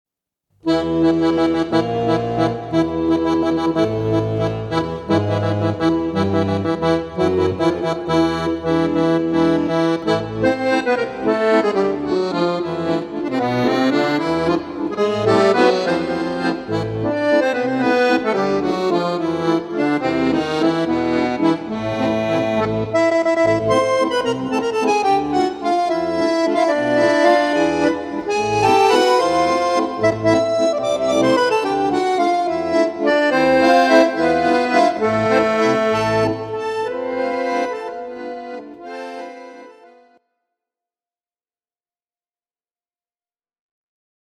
accordion solos
(Solo)